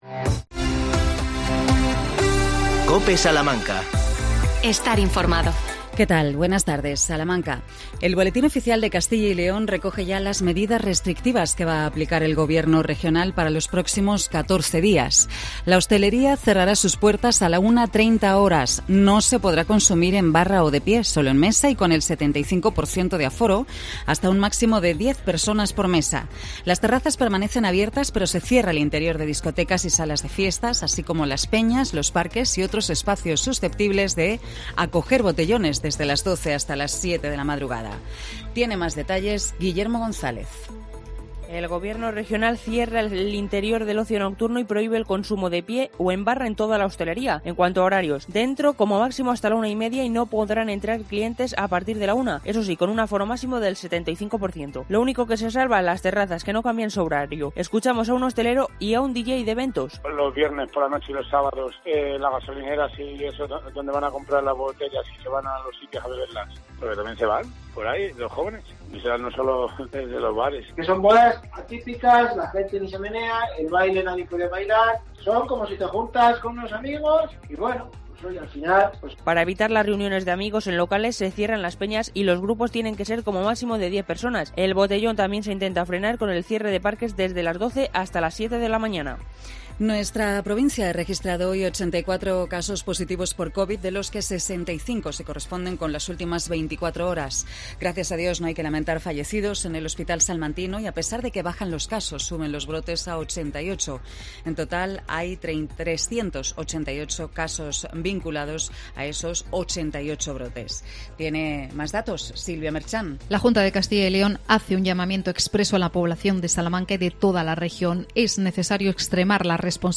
20 07 21 INFORMATIVO MEDIODIA COPE SALAMANCA